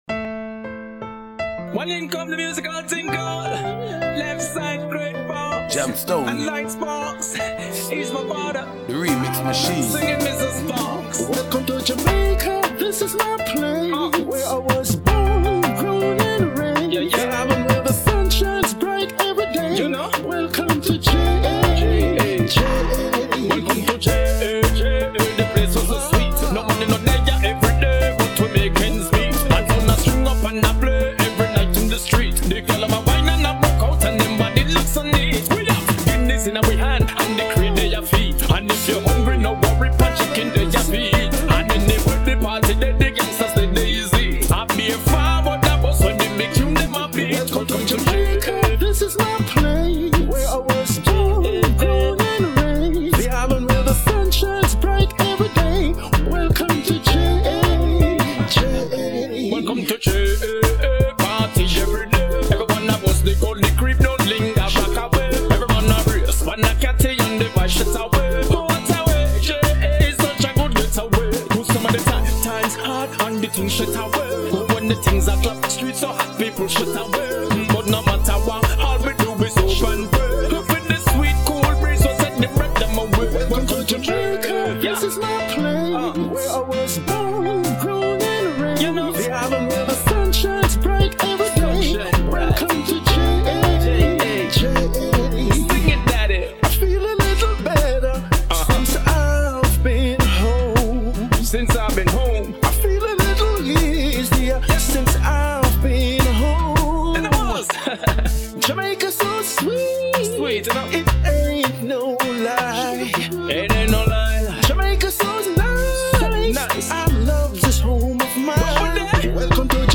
Vocal track